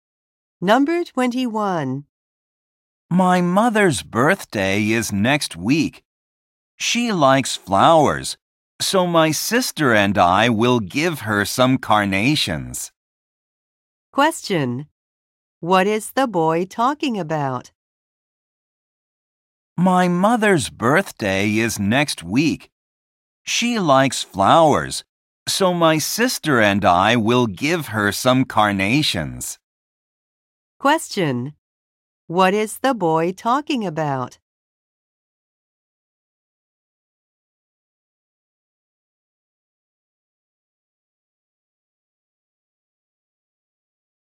英文はすべて2回放送され、読まれるスピードはかなりゆっくりです。